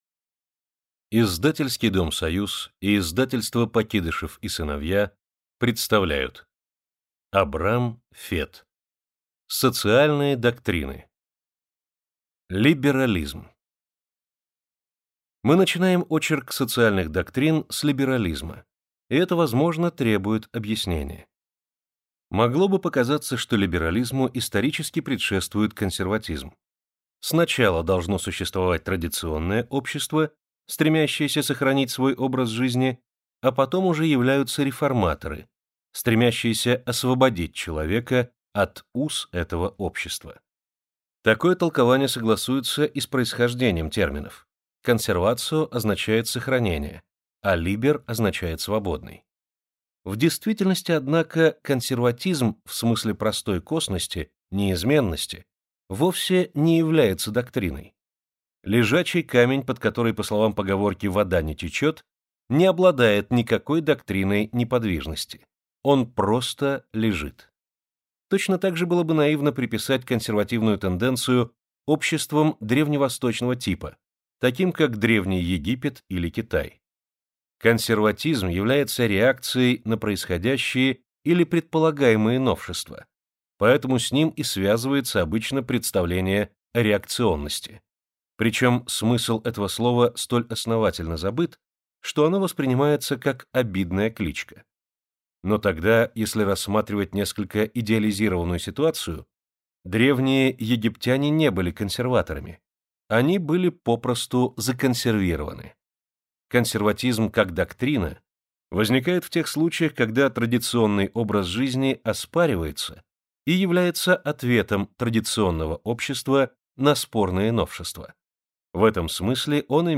Аудиокнига Социальные доктрины | Библиотека аудиокниг